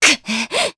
Aselica-Vox_Damage_jp_03.wav